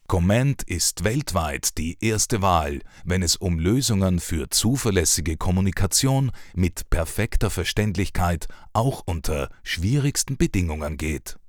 DE RK EL 02 eLearning/Training Male German